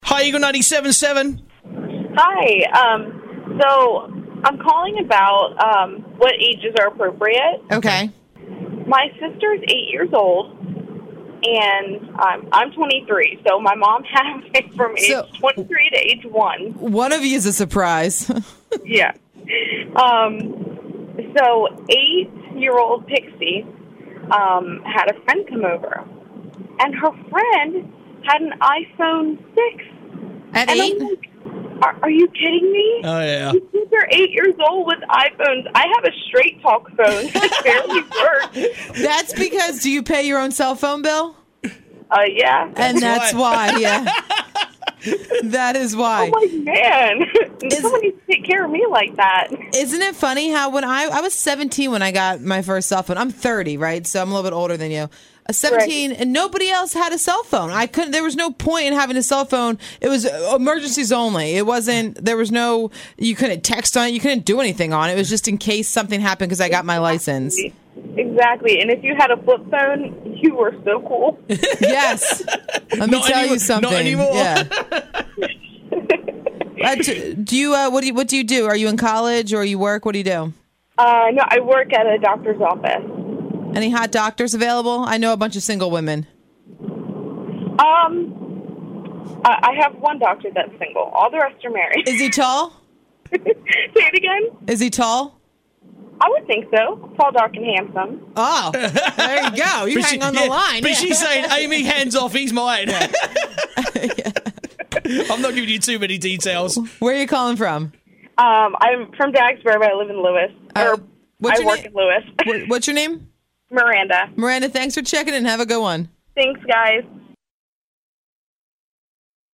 Kids doing things for the first time - Your Calls